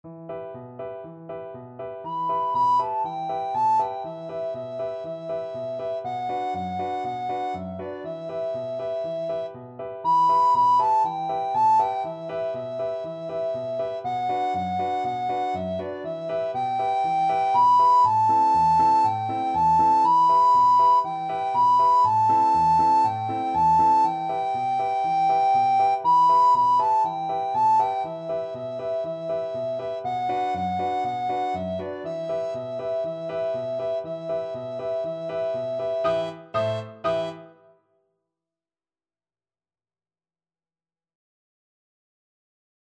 Free Sheet music for Soprano (Descant) Recorder
Allegro vivo (View more music marked Allegro)
4/4 (View more 4/4 Music)
World (View more World Recorder Music)